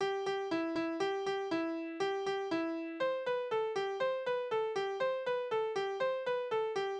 Spielverse: Da hast du 'nen Taler
Tonart: C-Dur
Taktart: 2/4
Tonumfang: kleine Sexte